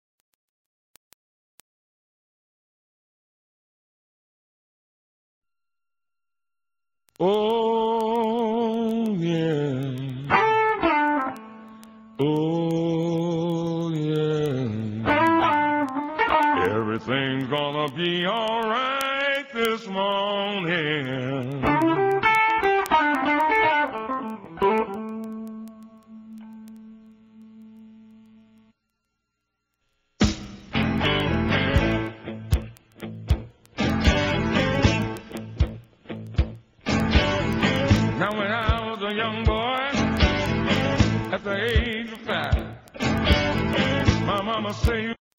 BLUES KARAOKE MUSIC CDs